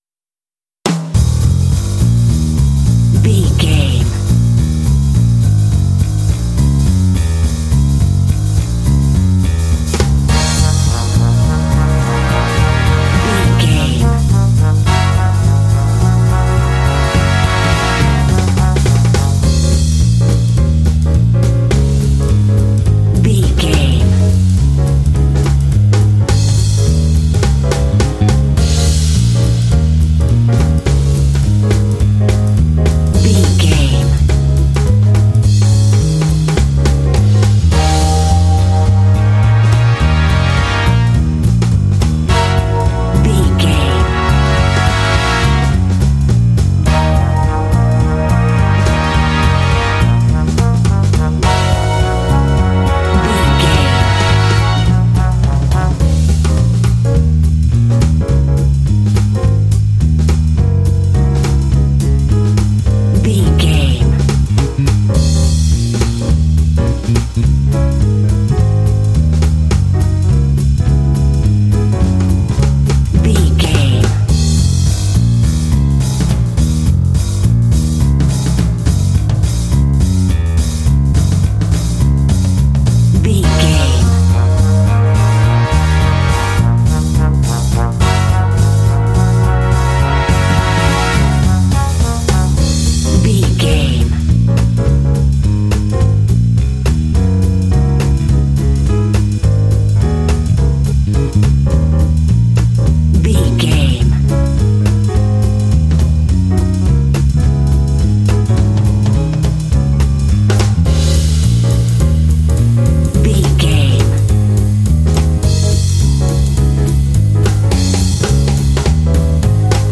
Aeolian/Minor
E♭
energetic
groovy
lively
bass guitar
piano
drums
brass
jazz
big band